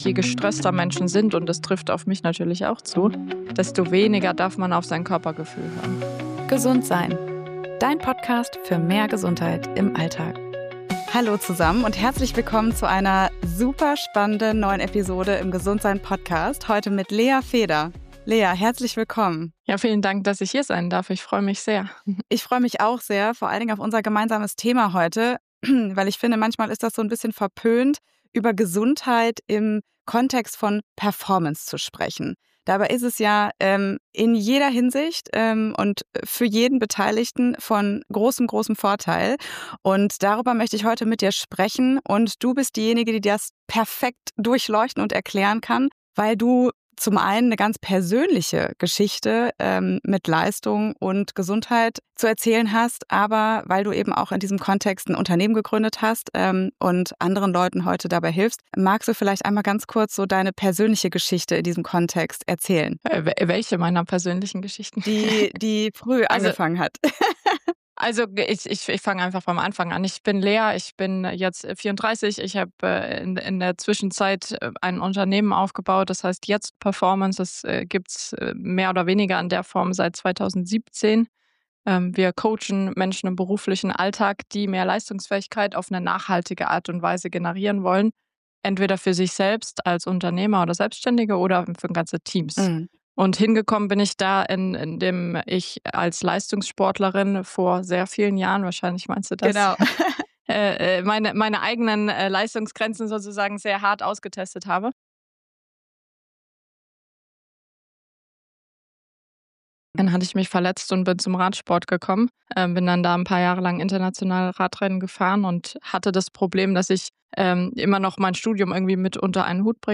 Ein Gespräch über den Unterschied zwischen Leistung um jeden Preis und gesunder Leistungsfähigkeit, die bleibt.